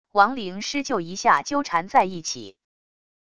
亡灵狮鹫一下纠缠在一起wav音频